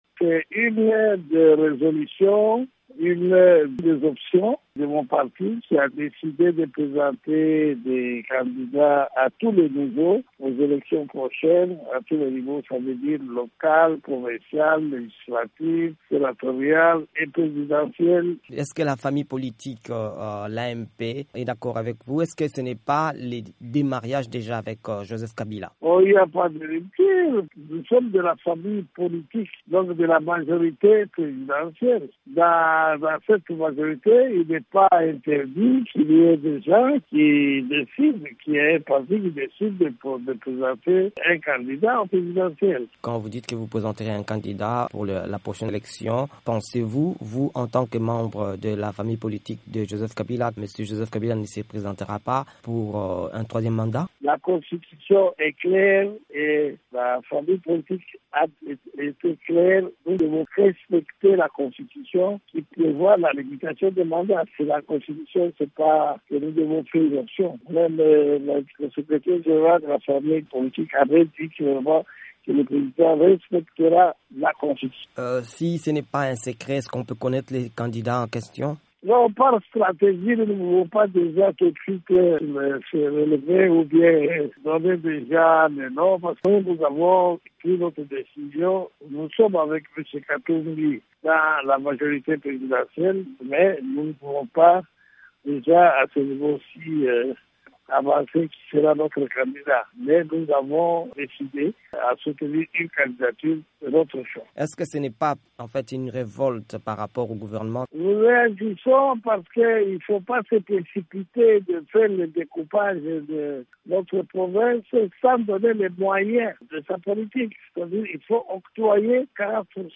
La rétrocession des 40 pourcents de revenus aux provinces ainsi que le découpage territorial présentement en cours en RDC sont d’autres sujets que Kyungu aborde dans l’interview qu’il a accordée à VOA.